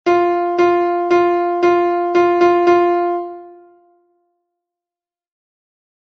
Ear traning